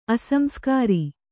♪ asamskāri